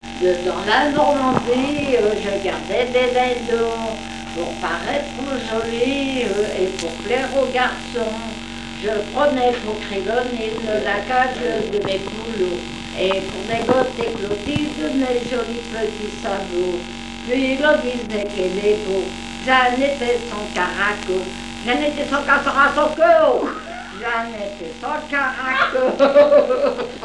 Genre strophique
Chansons traditionnelles
Pièce musicale inédite